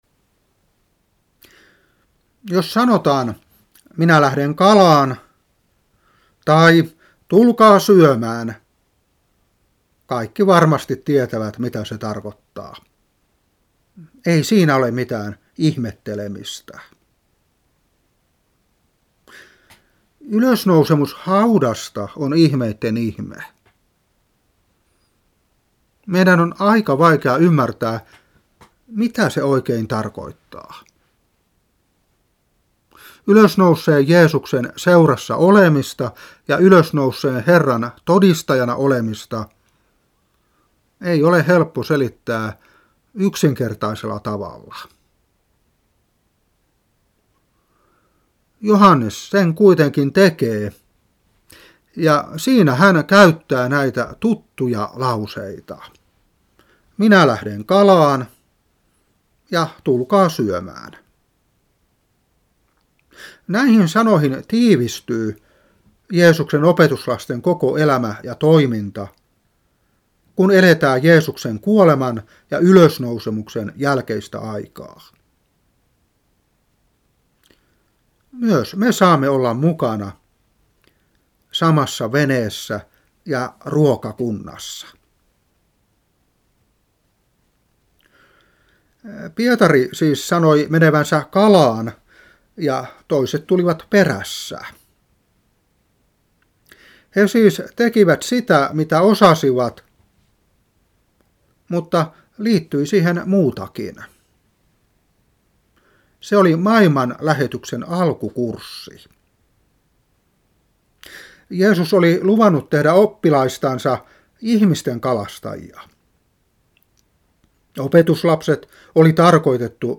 Saarna 2017-4.